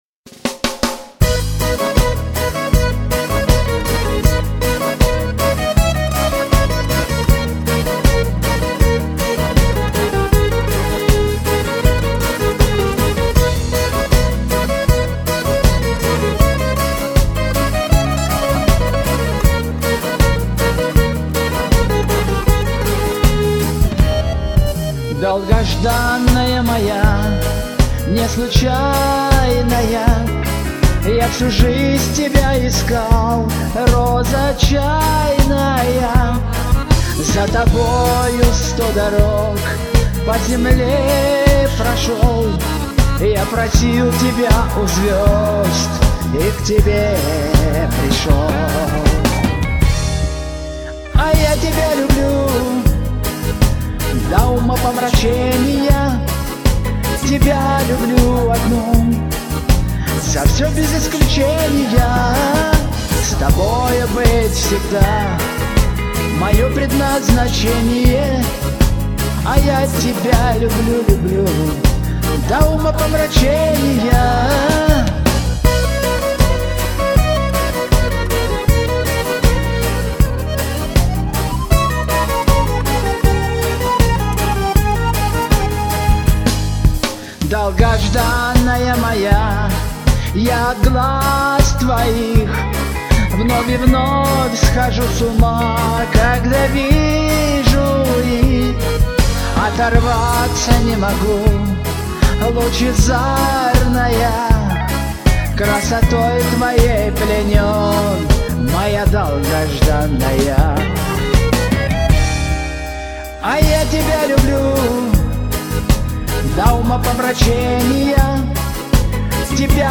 Песни разные , но спеты с душой , со страстью.